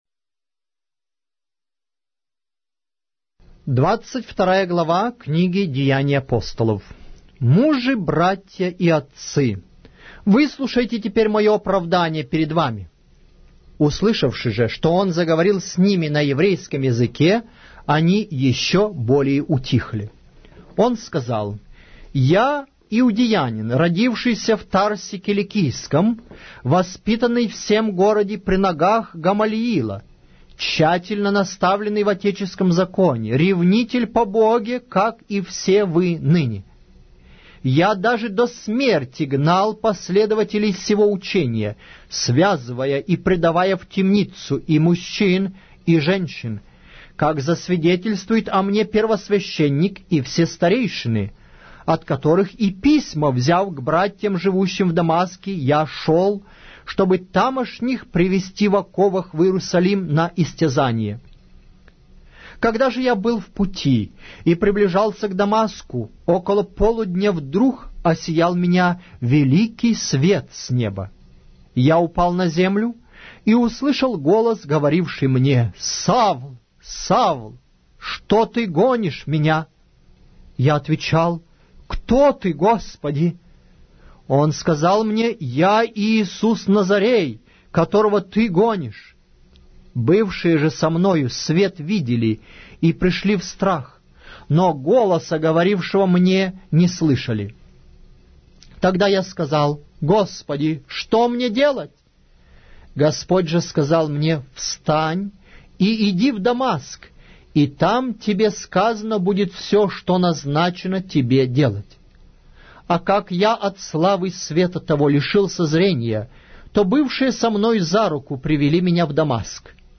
Аудиокнига: Деяния Апостолов